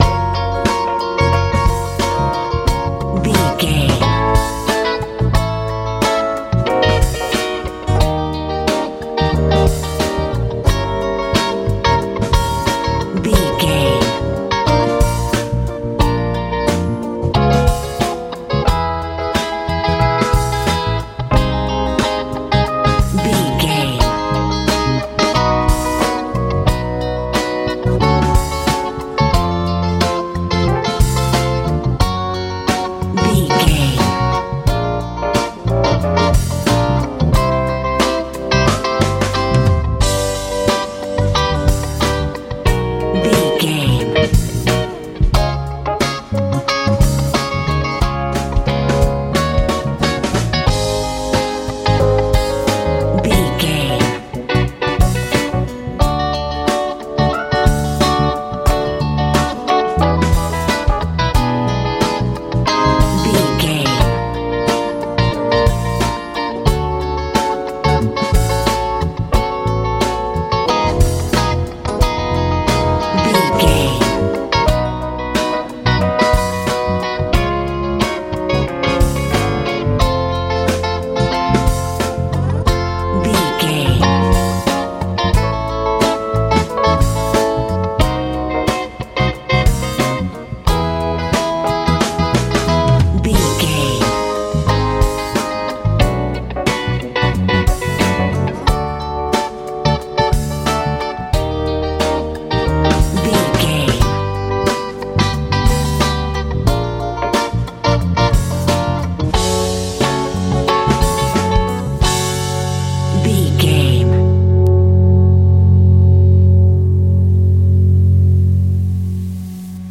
soul
Aeolian/Minor
B♭
electric guitar
electric piano
bass guitar
drums
soft
smooth
sensual